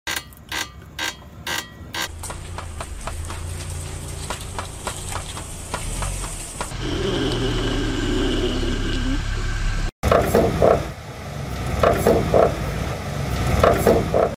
Car Problems Sounds Part 2 Sound Effects Free Download
car problem sounds engine noise issues vehicle troubleshooting strange car noises car diagnostic engine knocking transmission noise brake failure sounds suspension problems exhaust noise weird car sounds